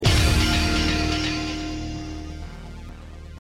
dramatic-sting.mp3